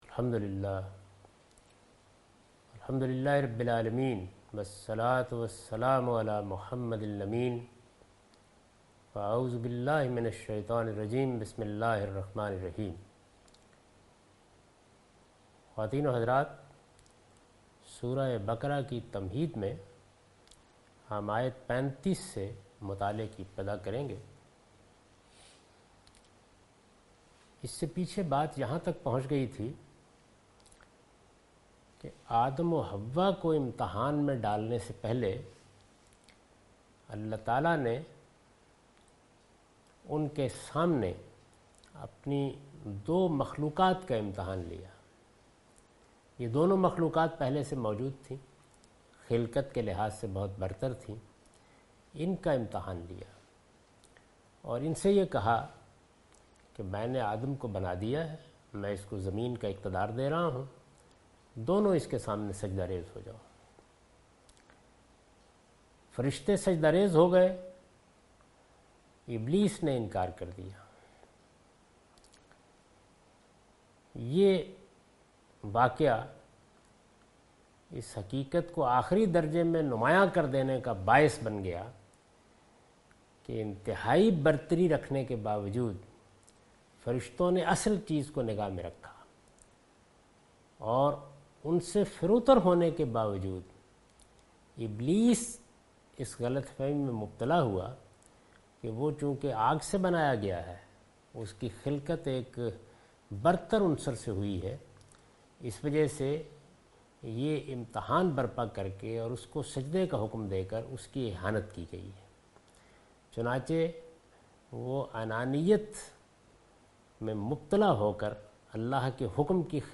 Surah Al-Baqarah - A lecture of Tafseer-ul-Quran – Al-Bayan by Javed Ahmad Ghamidi. Commentary and explanation of verse 35,36 and 37 (Lecture recorded on 25th April 2013).